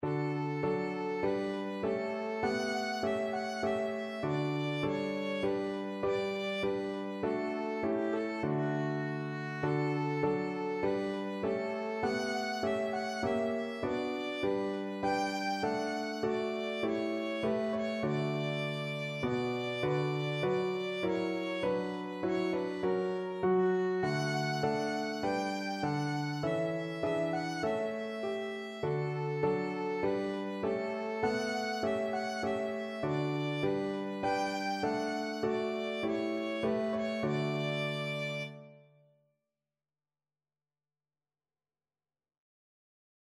Violin
4/4 (View more 4/4 Music)
D major (Sounding Pitch) (View more D major Music for Violin )
Classical (View more Classical Violin Music)